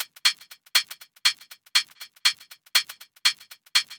pdh_120_drum_loop_hidden_hat.wav